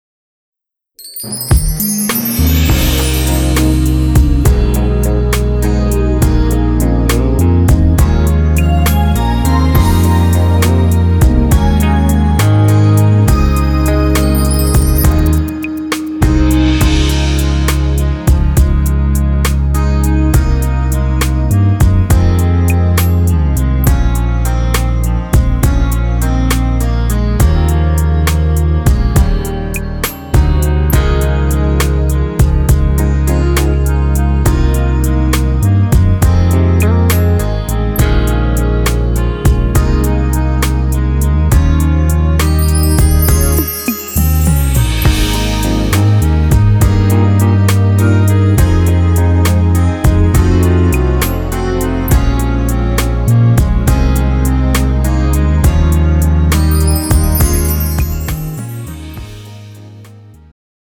음정 남자-1키
장르 축가 구분 Pro MR